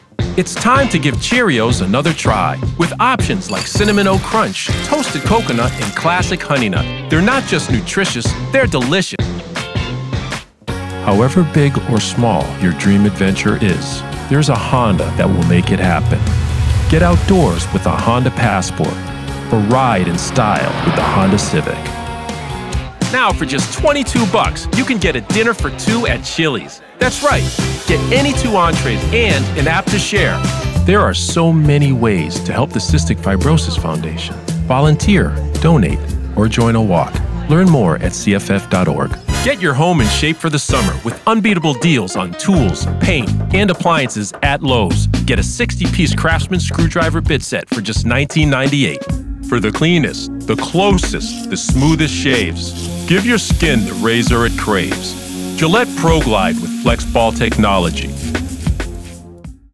Commercial Demo
Middle Aged
My voice is distinctive, rich and full of resonance, while sounding familiar.